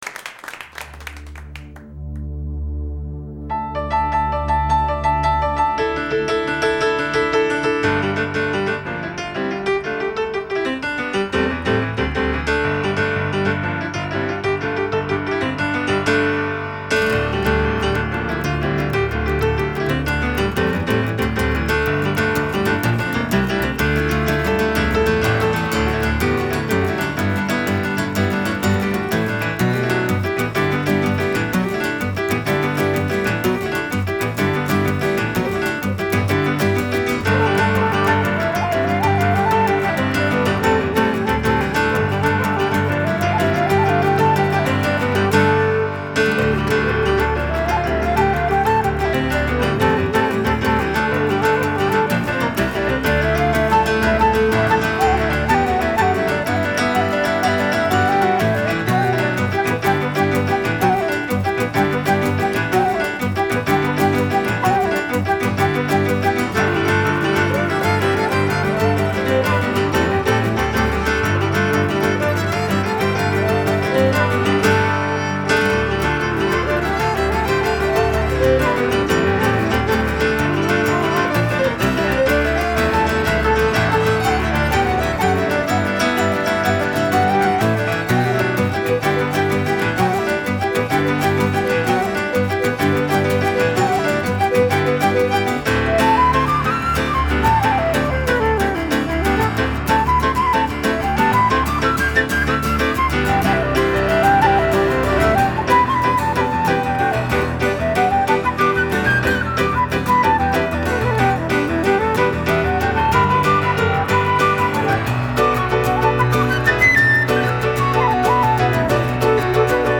爵士/世界音乐
主奏乐器：人声、民族乐器
让传统民谣显得轻盈灵巧，
其余的曲子是在俄勒冈波特兰的White Horse Studio录音室录制的。